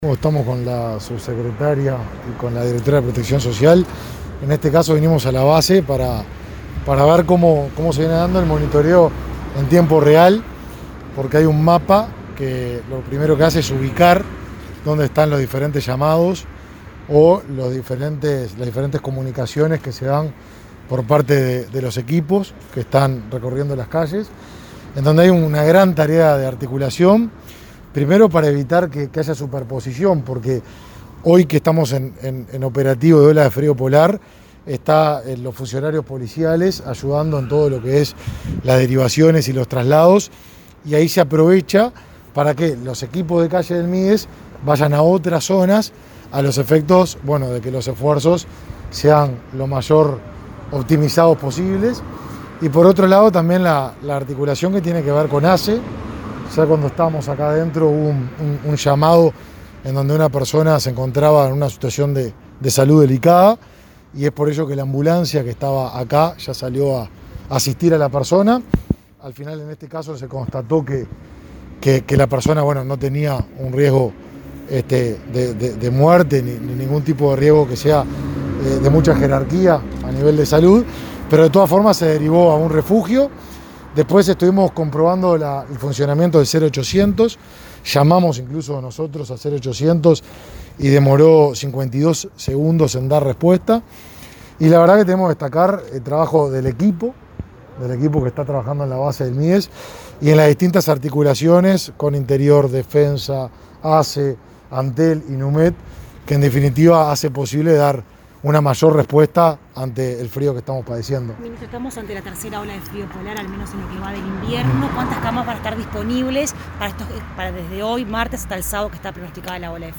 Declaraciones del ministro de Desarrollo Social, Martín Lema, sobre operativo Frío Polar
Este martes 28, Lema brindó una conferencia de prensa en la que informó acerca del operativo Frío Polar, que comenzó hoy y se extenderá hasta el